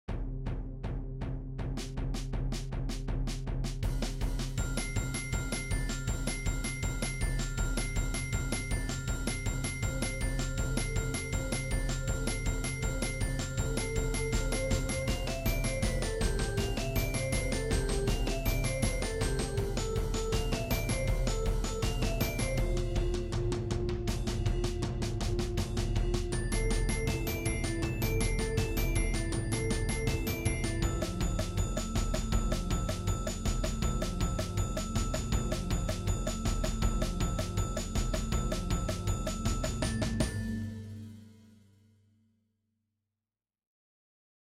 Jeugd Ensemble